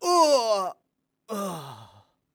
xys死亡7.wav 0:00.00 0:02.35 xys死亡7.wav WAV · 203 KB · 單聲道 (1ch) 下载文件 本站所有音效均采用 CC0 授权 ，可免费用于商业与个人项目，无需署名。
人声采集素材